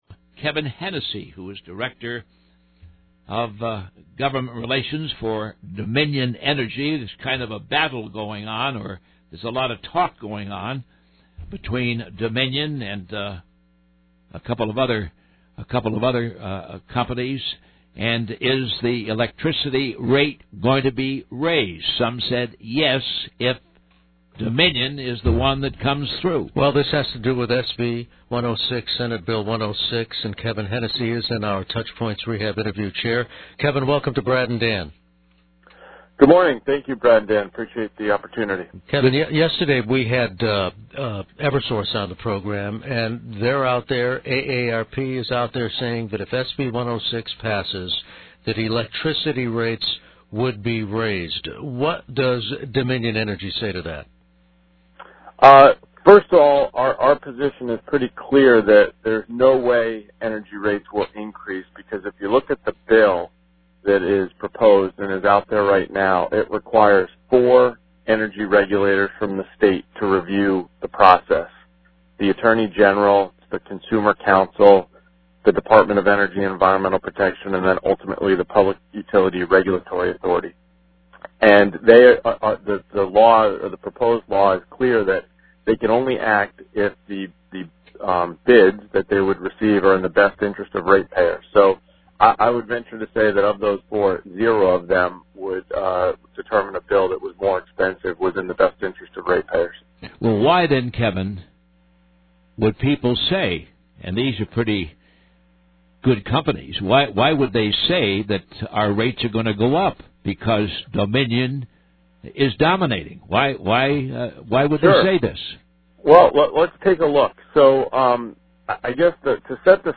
Dominion Energy is countering claims that SB 106, a bill before the Connecticut General Assembly, would give it an advantage and pave the way for electricity rate hikes. In this interview